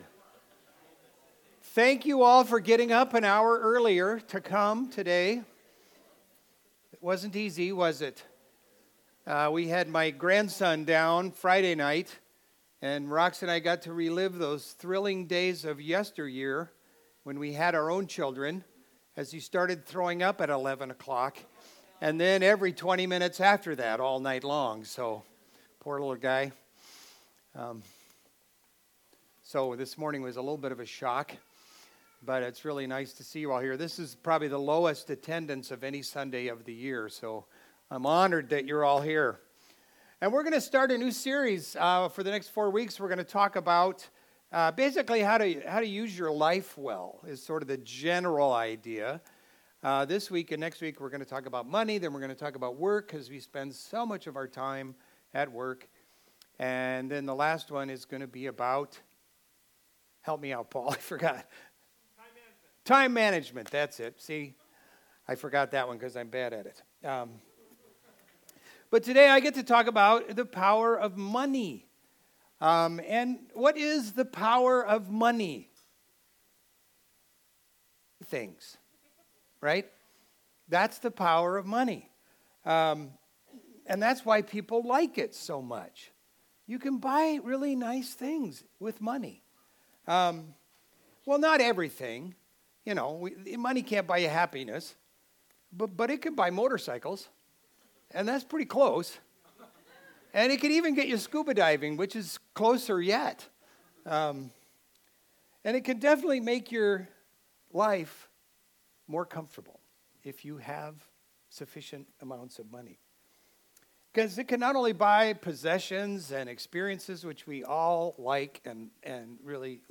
Video Audio Download Audio Home Resources Sermons The Power of Money Mar 08 The Power of Money Money has the power to bring great good or evil into our lives.